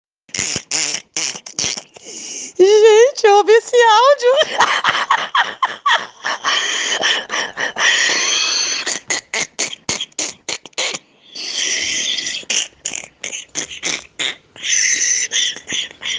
Play, download and share Risada EngraçadaVia original sound button!!!!
risada-engracadavia.mp3